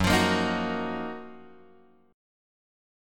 F# Major 13th
F#M13 chord {2 x 3 3 4 2} chord